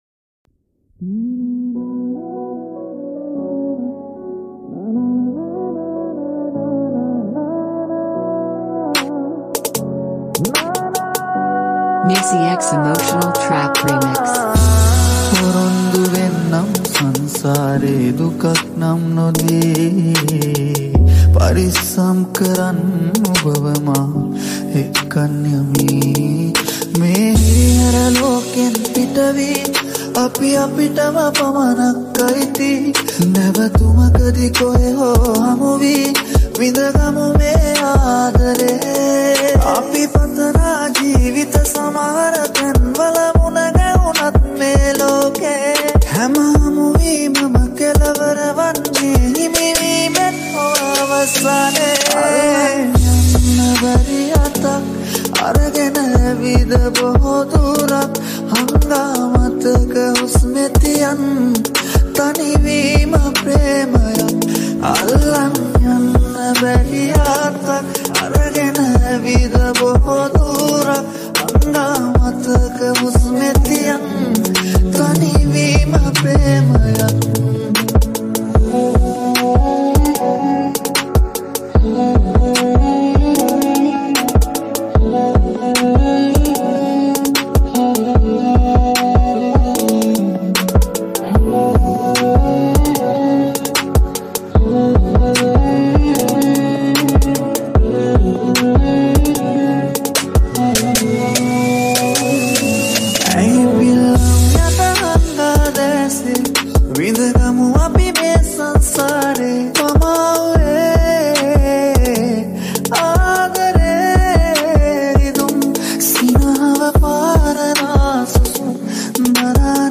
Trap n Bass Boosted Remix